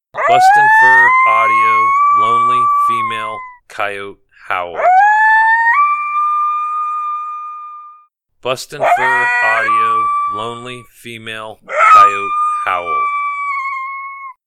BFA Lonely Female Coyote Howl
Lonely Female Coyote Howl is a short series of Lone Howls done by our popular female Beans.
BFA Lonely Female Coyote Howl Sample.mp3